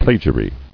[pla·gia·ry]